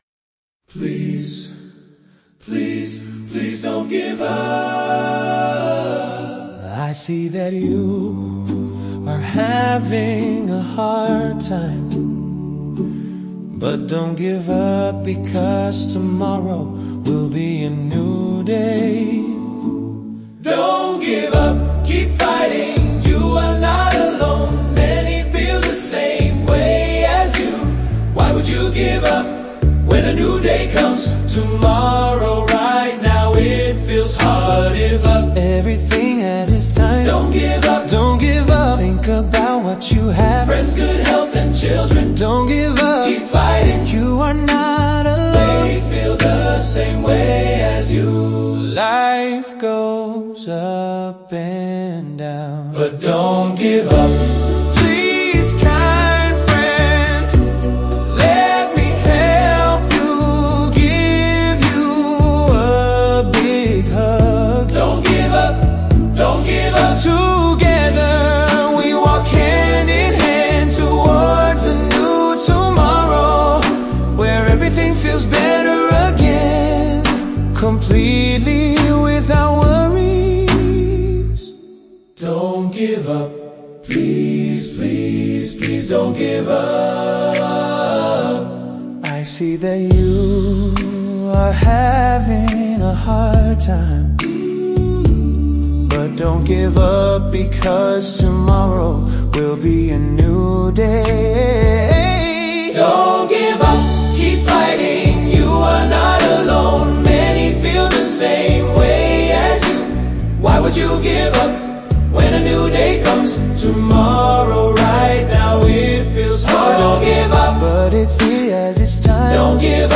VI FIVE blir en ny grupp inom gengerna pop/rock.